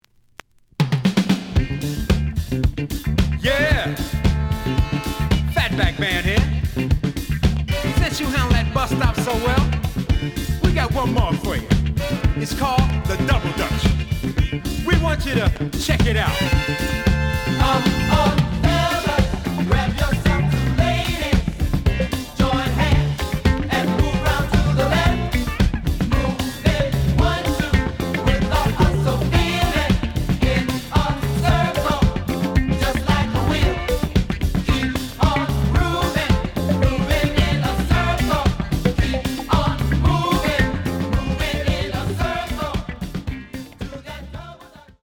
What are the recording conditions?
The audio sample is recorded from the actual item. A side plays good.